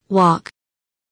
walk kelimesinin anlamı, resimli anlatımı ve sesli okunuşu